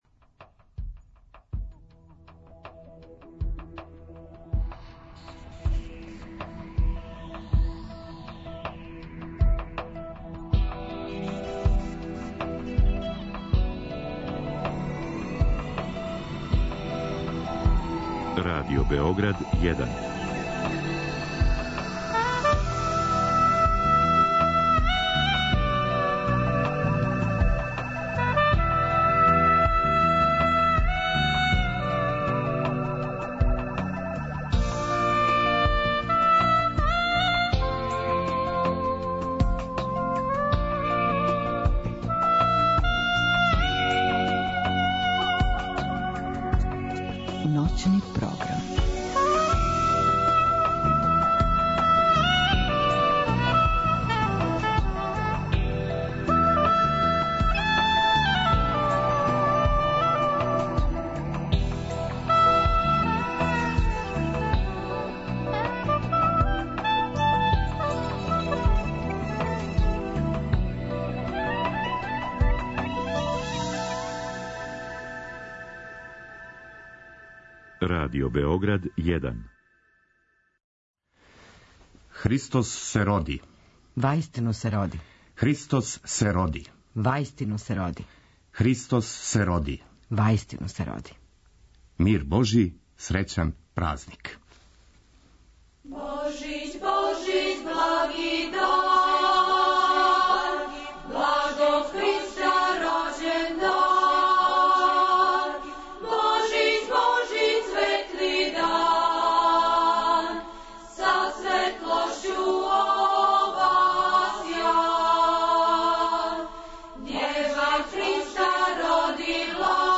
Вечерас смо се потрудили да уз добре, старе народне песме дочекате један од најрадоснијих хришћанских празника, Божић!